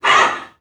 NPC_Creatures_Vocalisations_Robothead [20].wav